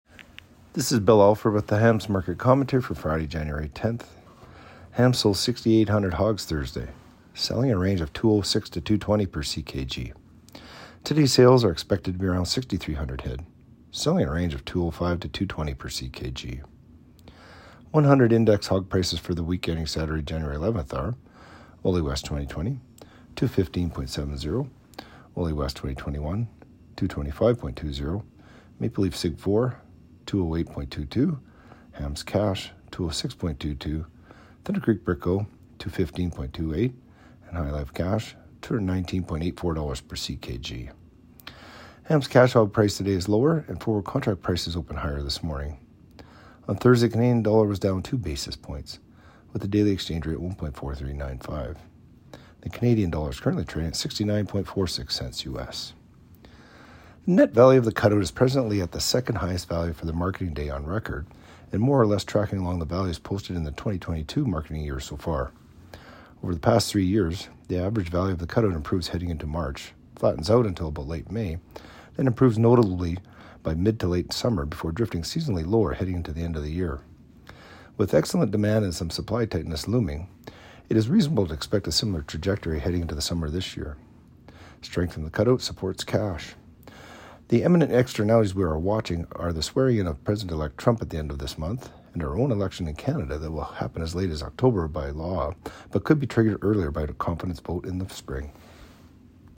Market-Commentary-Jan.-10-25.mp3